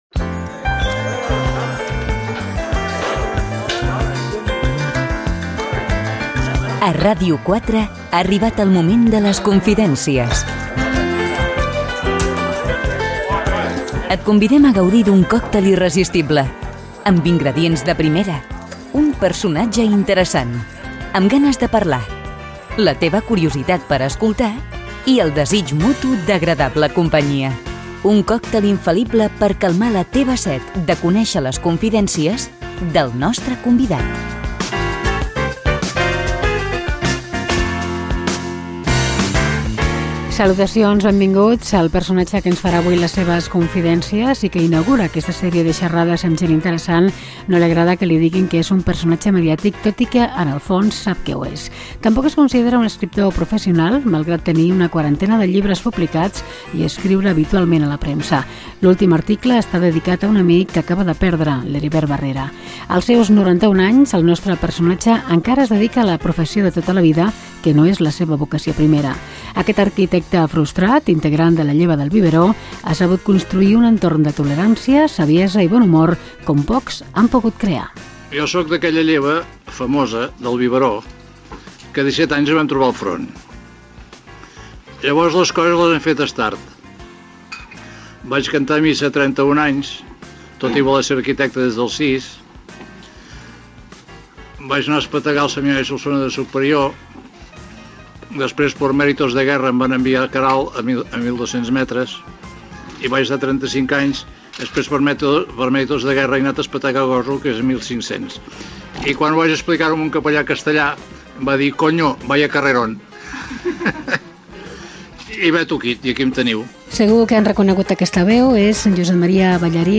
Careta del programa
perfil biogràfic i entrevista
Entreteniment